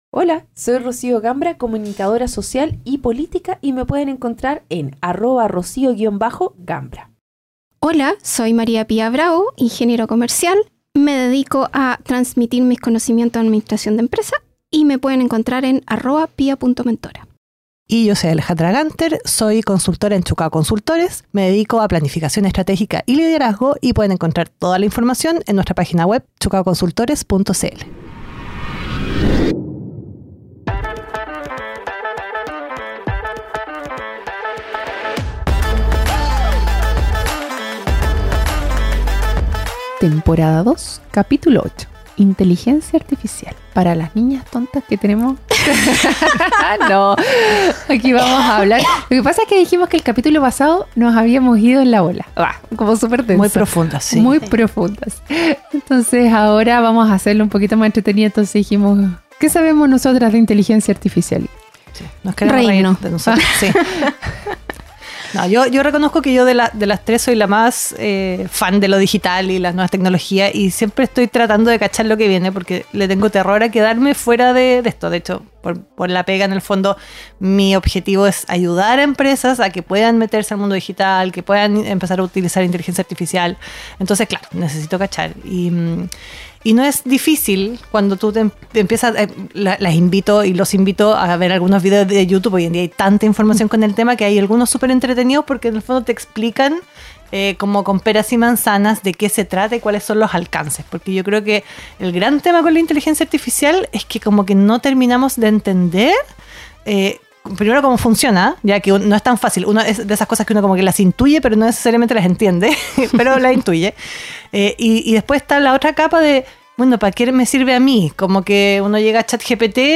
Somos tres amigas